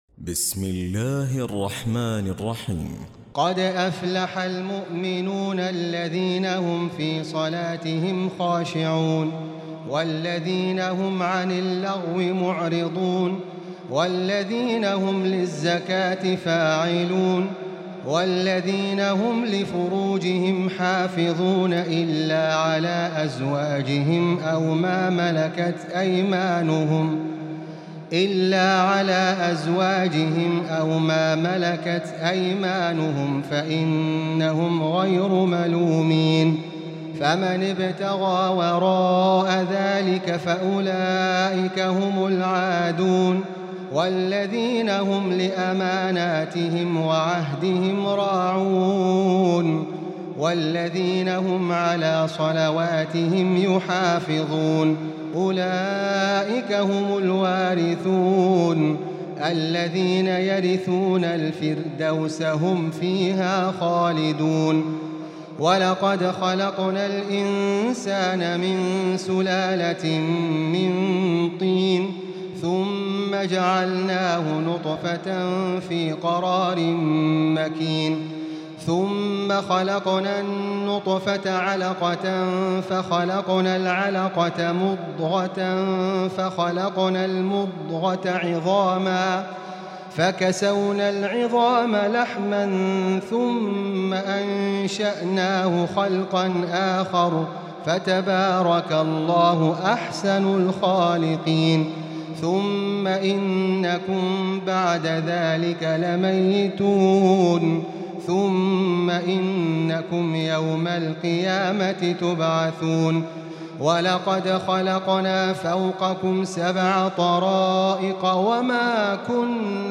تراويح الليلة السابعة عشر رمضان 1438هـ سورتي المؤمنون و النور (1-20) Taraweeh 17 st night Ramadan 1438H from Surah Al-Muminoon and An-Noor > تراويح الحرم المكي عام 1438 🕋 > التراويح - تلاوات الحرمين